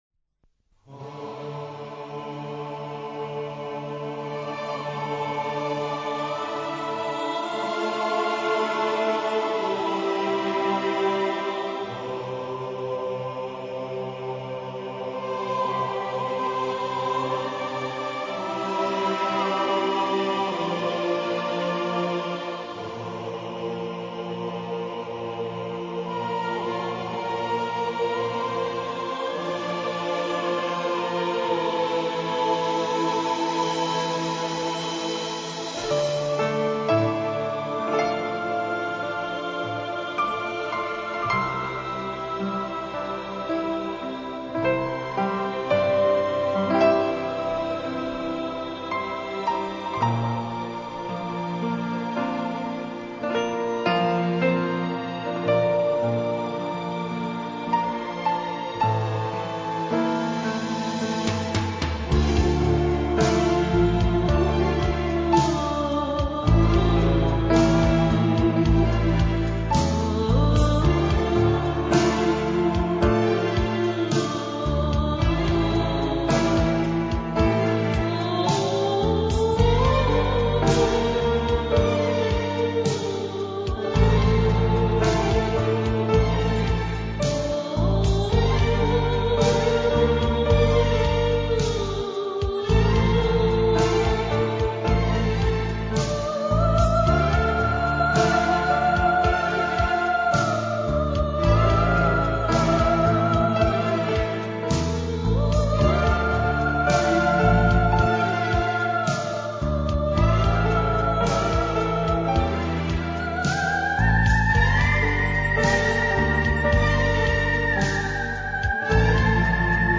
موسیقی بیکلام
موسیقی متن